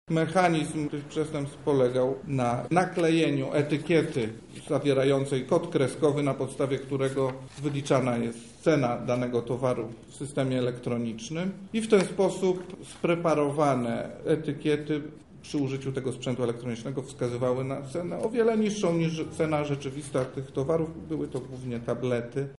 – mówi Sędzia Sądu Rejonowego Andrzej Wach.